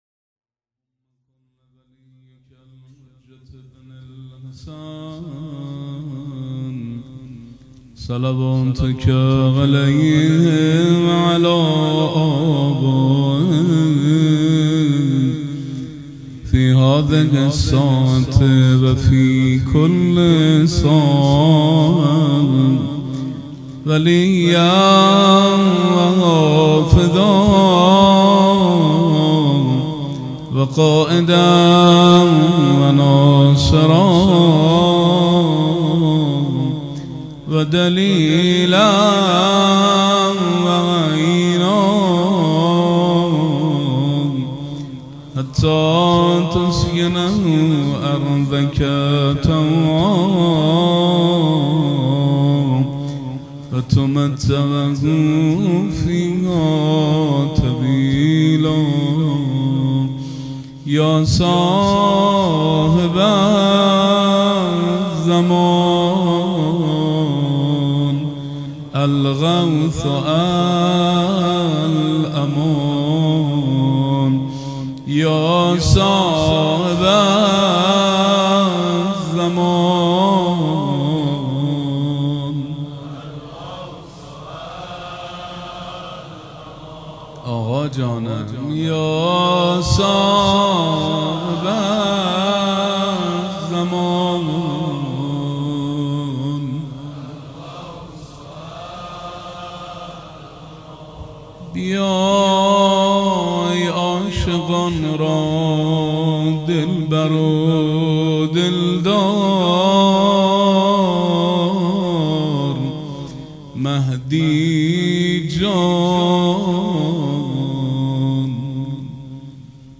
سخنرانی
سخنرانی شب شهادت امام صادق
سخنرانی.mp3